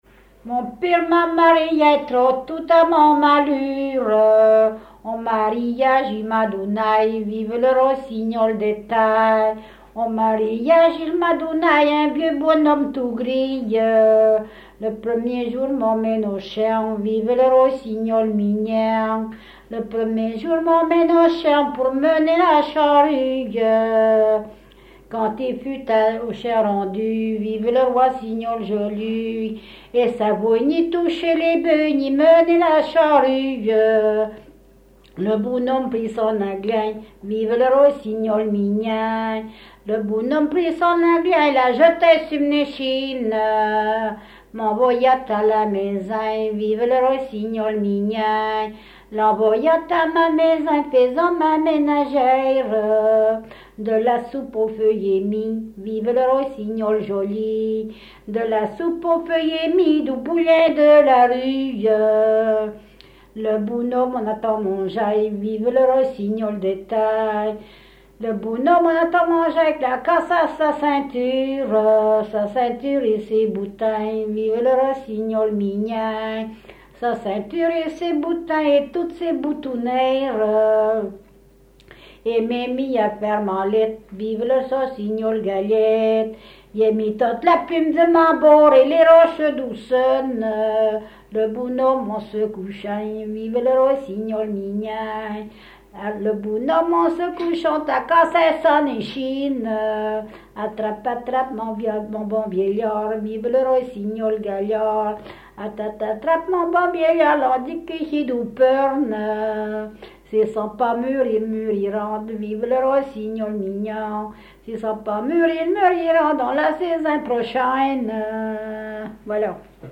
Ile de Noirmoutier
Genre laisse
Catégorie Pièce musicale inédite